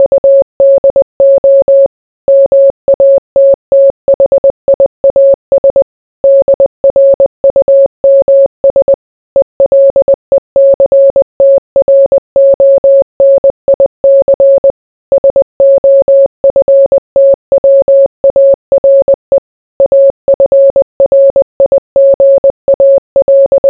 morse1.wav